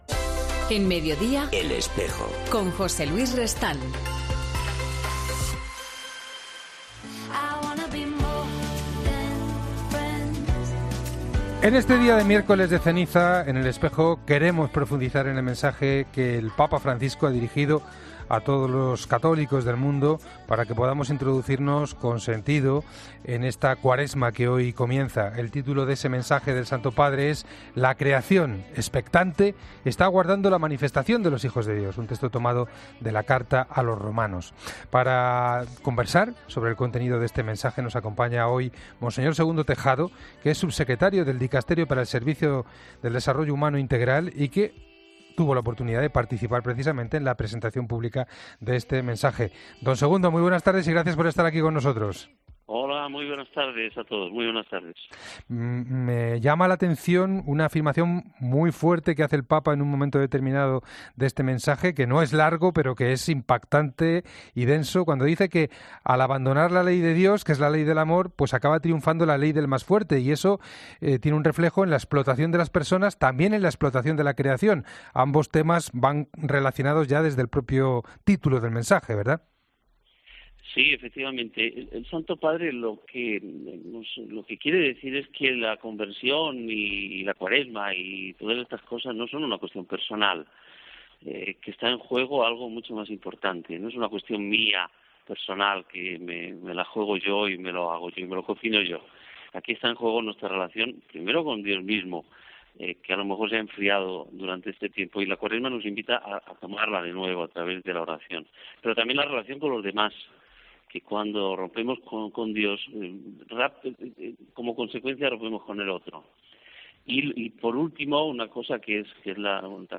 Profundizamos en el mensaje del Papa para la Cuaresma con el Subsecretario del  Dicasterio para el Servicio del Desarrollo Humano Integral.